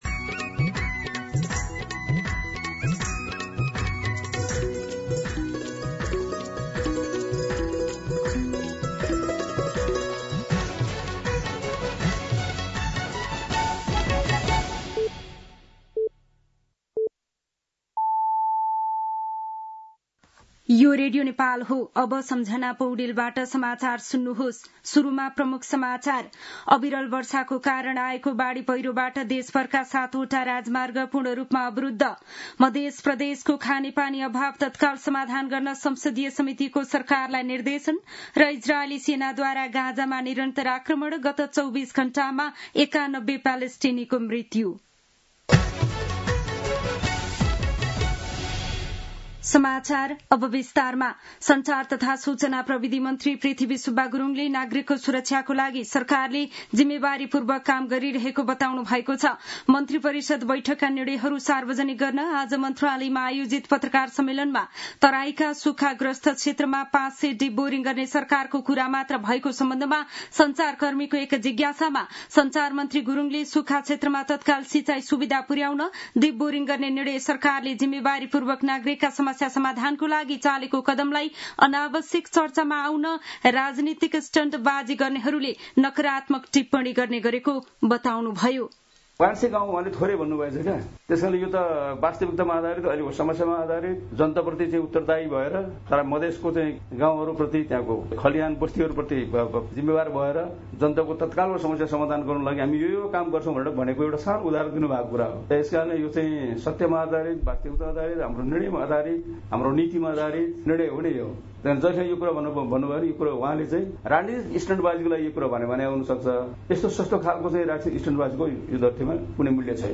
दिउँसो ३ बजेको नेपाली समाचार : १५ साउन , २०८२